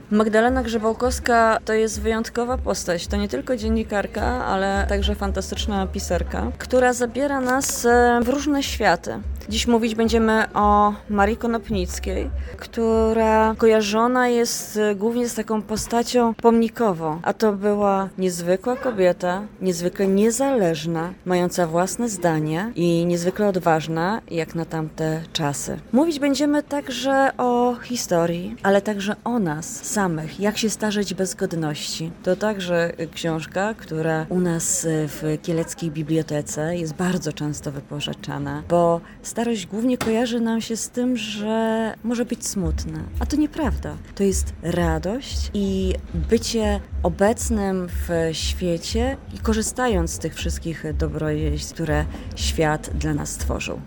W czwartek, 6 listopada, w Poczytalni na Dworcu odbyło się spotkanie z Magdaleną Grzebałkowską – znaną reporterką i biografistką.